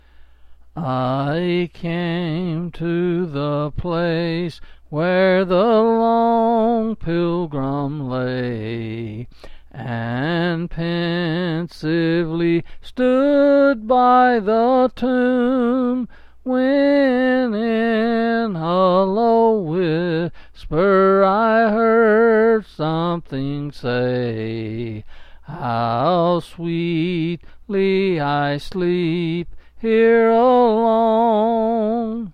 Quill Pin Selected Hymn
11s and 8s.